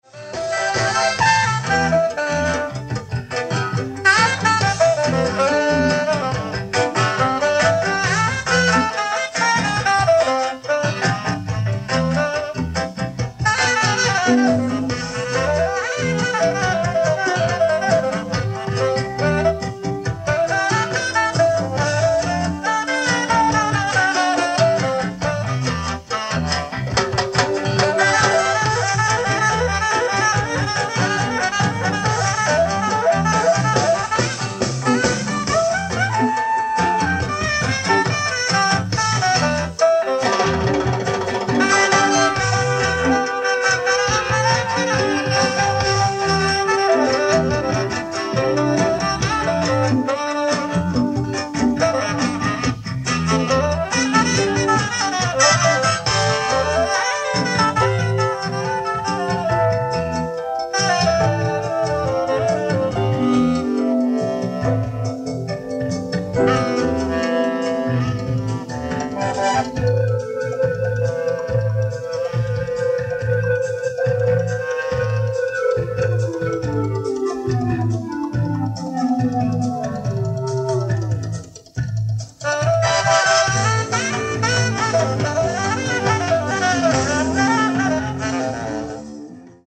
Live At Nagoya Shikikaido, Nagoya, Japan July 29, 1985